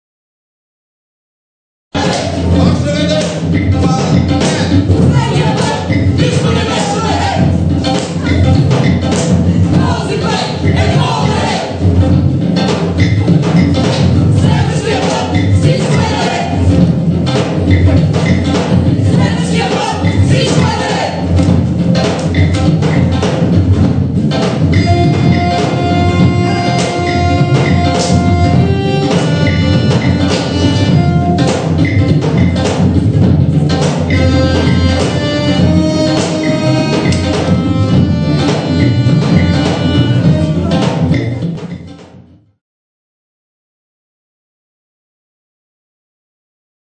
Cello & Elektronik
Tabla & Perc
Den musikalischen Rahmen werden spartanische Kammermusik und zeitgenössischer, weltmusikalischer Jazz unter Einbeziehung spezifischer Elemente der bulgarischen Folklore bilden.